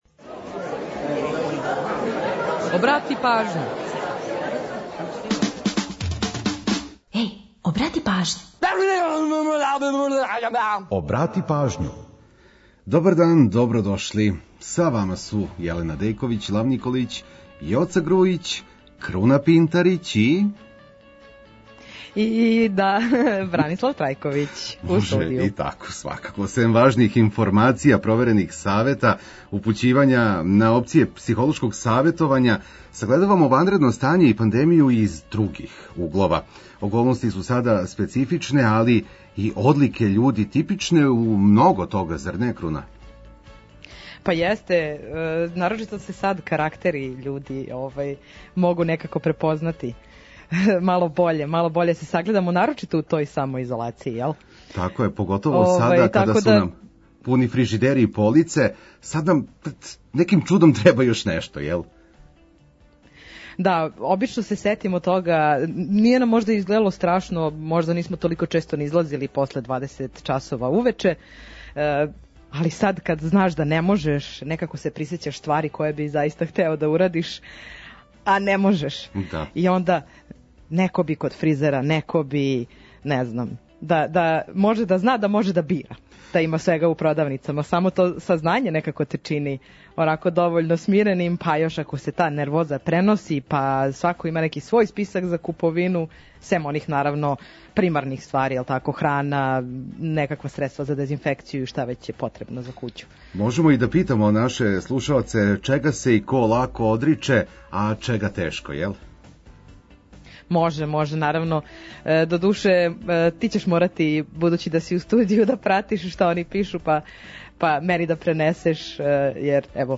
Ту је и пола сата резервисаних само за нумере из Србије и региона.